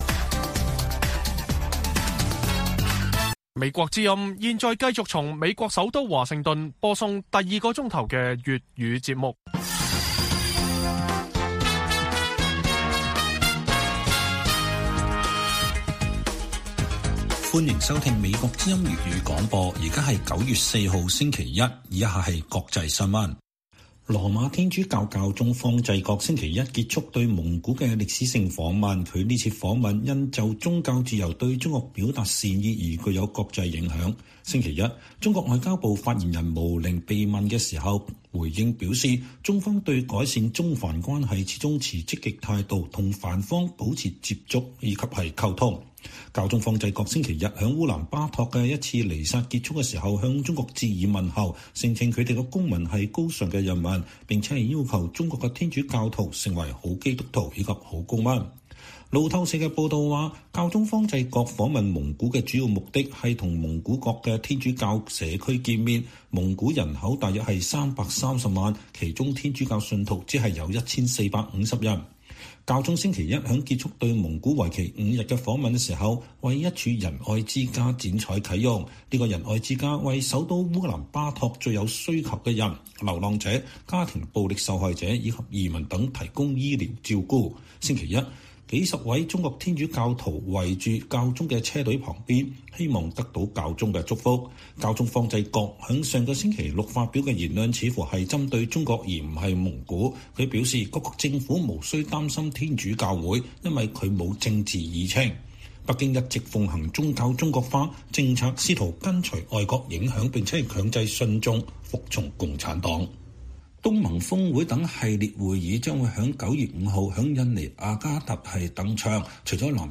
粵語新聞 晚上10-11點: 北京回應教宗對華訊息，稱對改善關係持積極態度